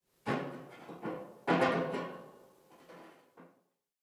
ca37fcf28b Divergent / mods / Soundscape Overhaul / gamedata / sounds / ambient / soundscape / underground / under_32.ogg 106 KiB (Stored with Git LFS) Raw History